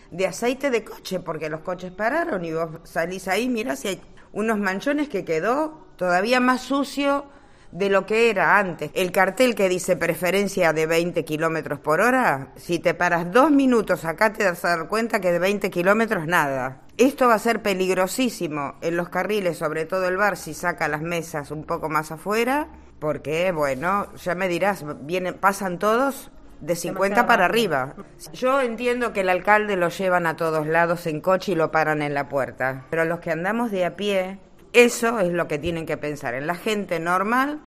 En COPE Santiago nos hemos dado una vuelta por la zona y hemos recogido las dudas de algunos vecinos y comerciantes.